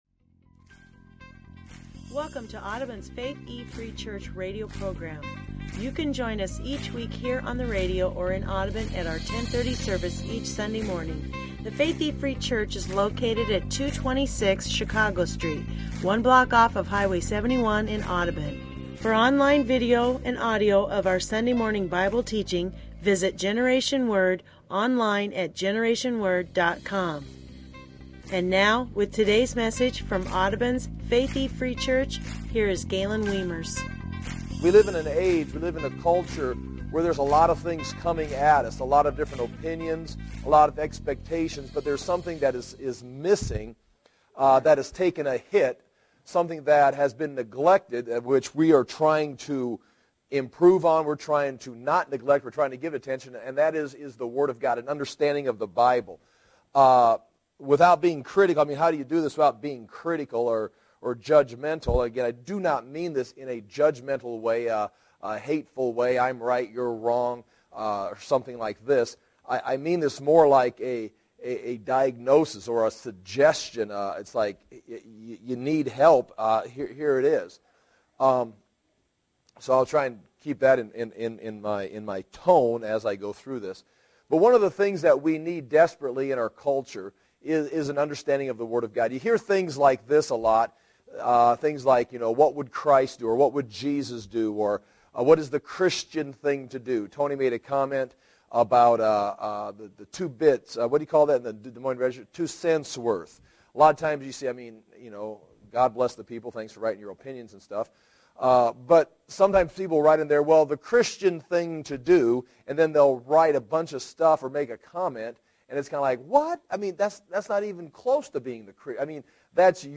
teaching-part1.mp3